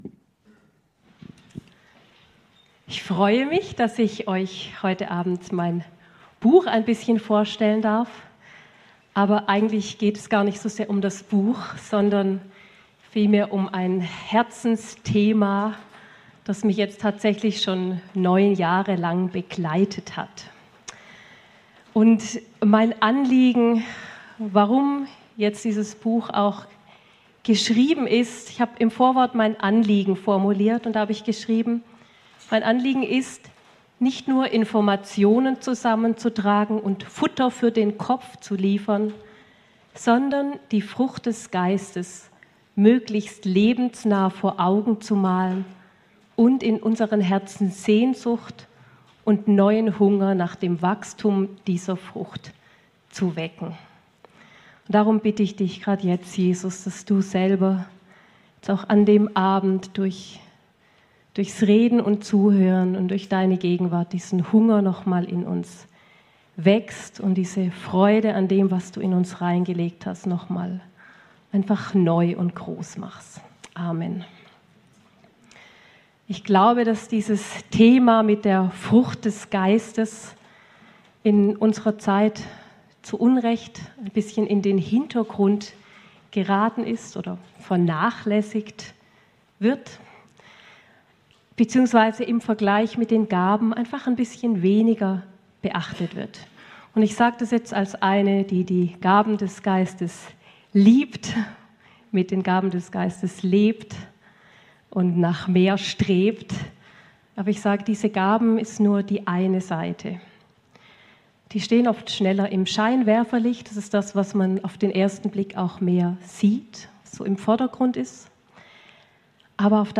Dienstagsgottesdienst vom 8.